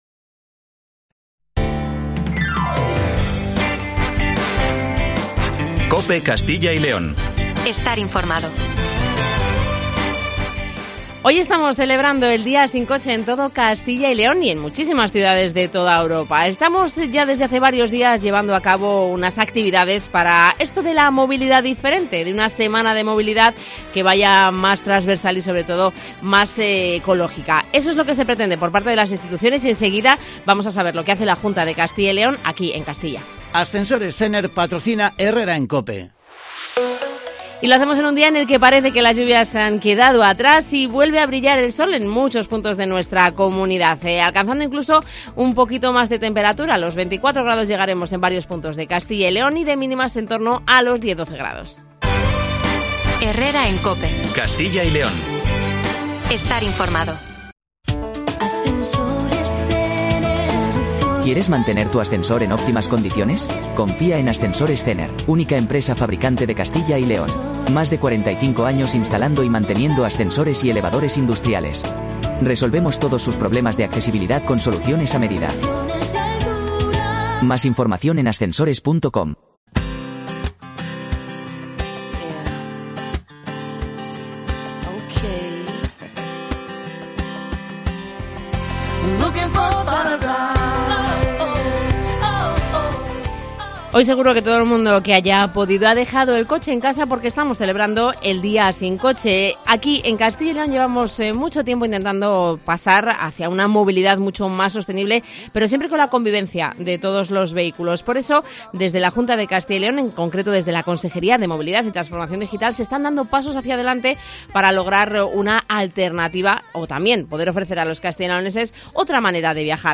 En el Día Mundial Sin Coche hablamos con la Consejera de Movilidad de la Junta de CyL, María González Corral, sobre los planes en marcha para conseguir una movilidad sostenible.